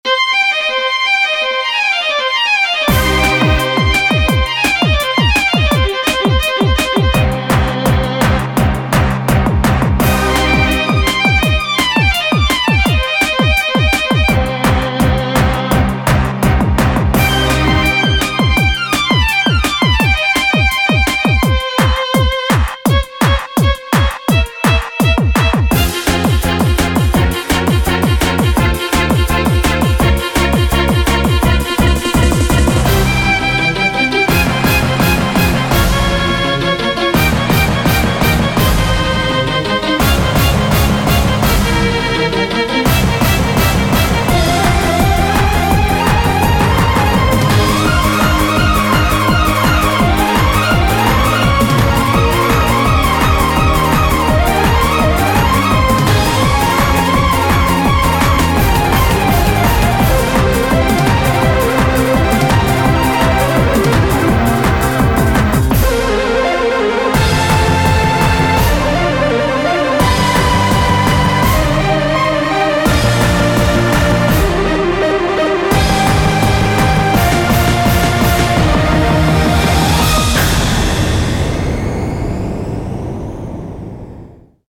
BPM168
Audio QualityPerfect (High Quality)
Remix